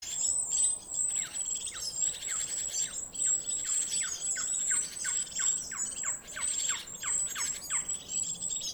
воробьиный сыч, Glaucidium passerinum
Примечания/izpr. atbild ar attālinoti melnai dzilnai līdzīgiem saucieniem